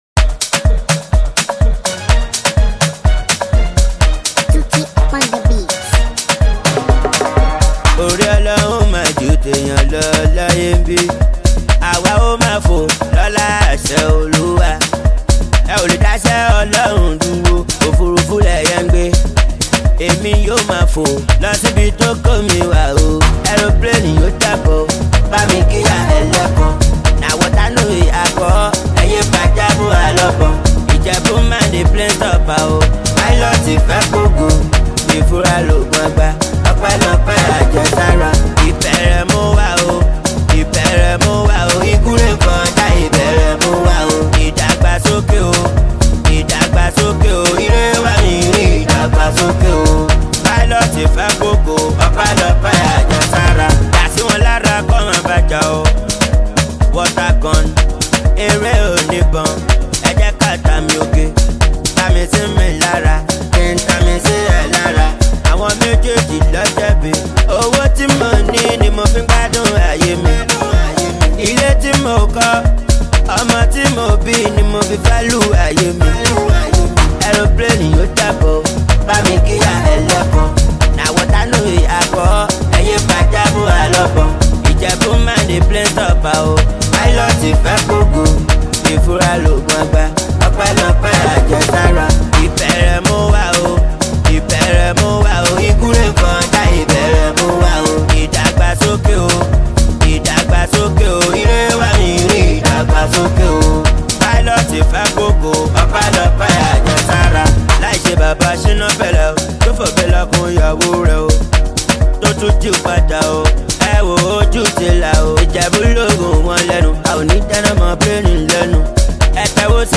Nigerian street-hop